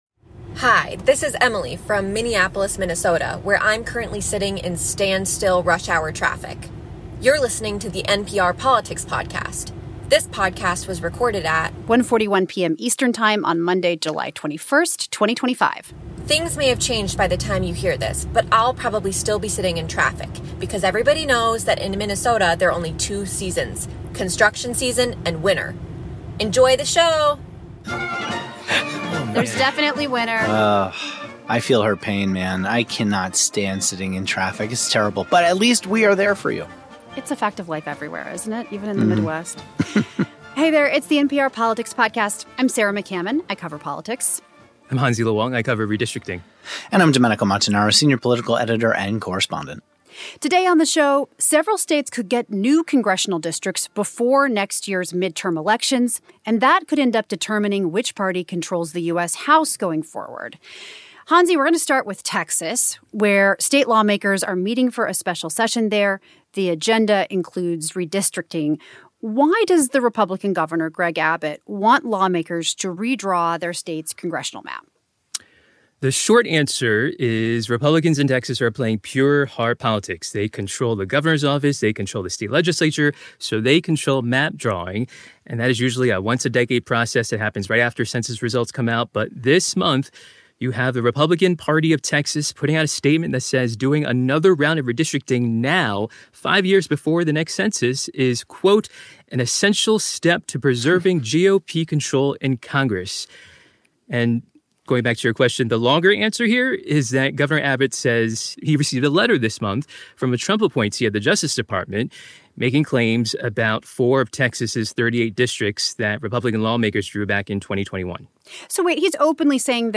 This episode: political correspondent Sarah McCammon, correspondent Hansi Lo Wang, and senior political editor and correspondent Domenico Montanaro.